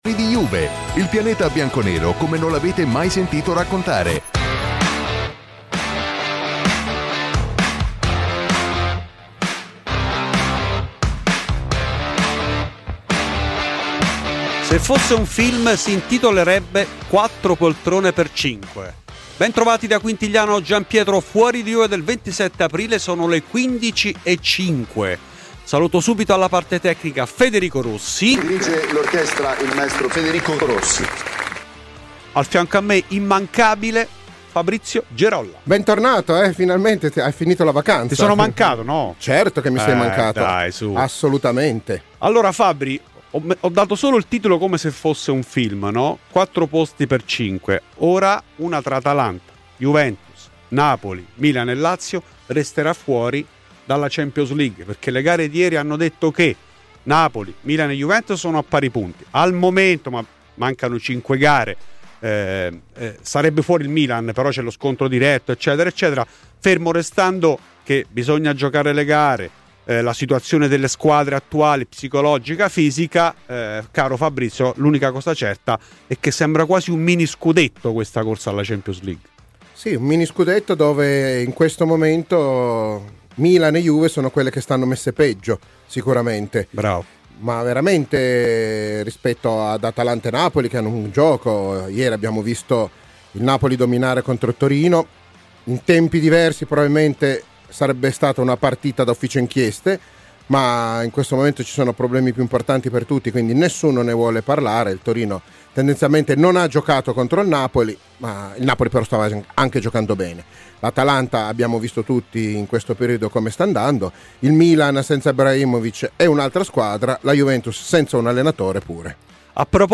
Clicca sul podcast in calce per ascoltare la trasmissione integrale.